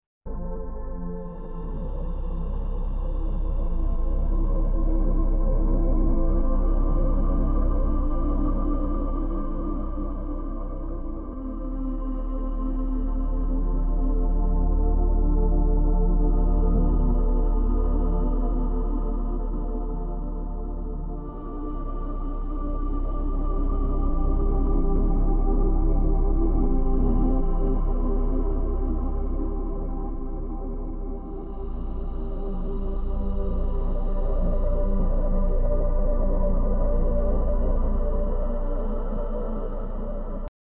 1111Hz + 432Hz + 741Hz sound effects free download